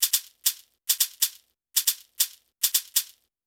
TYPEWRITER-R.wav